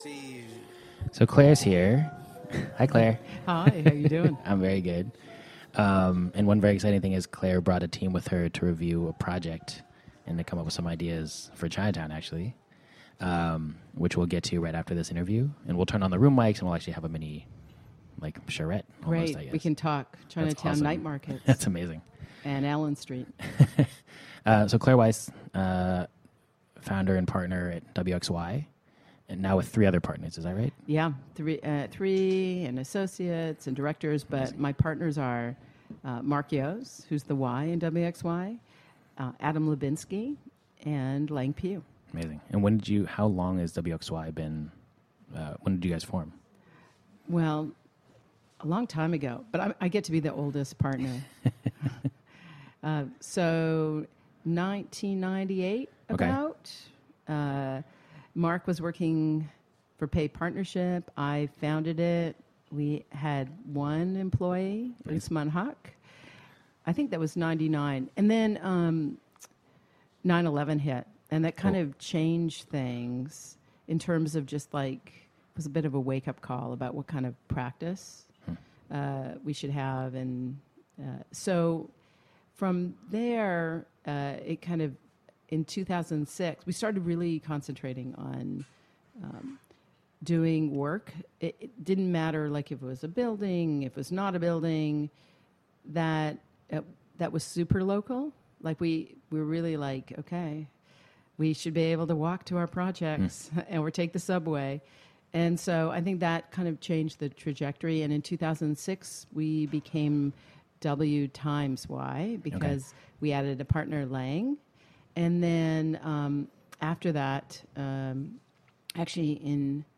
For season one, Office Hours , we took over a storefront in Chinatown and interviewed over 50 artists, designers, chefs, architects, entrepreneurs, and one politician, all to find out how they managed to make money doing what they love.
All of the interviews were recorded live.